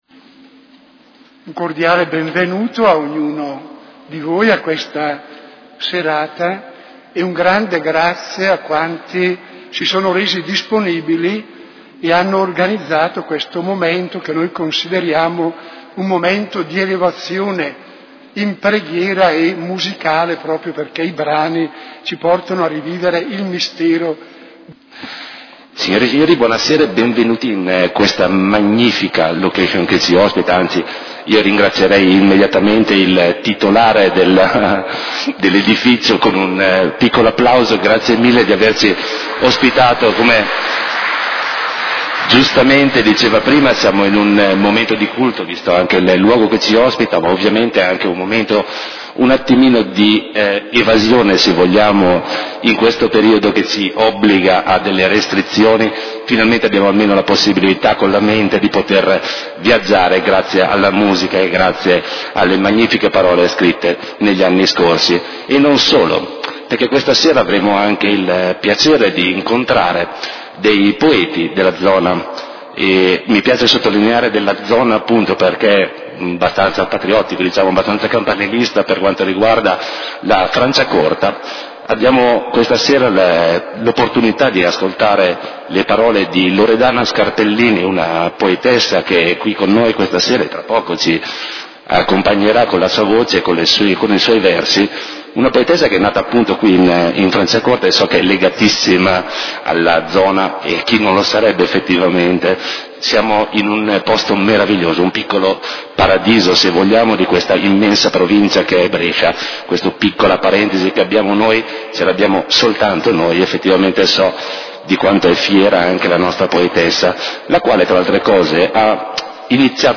Concerto dell'Epifania - 6 gennaio 2022
concerto_epifania_2022_mixdown.mp3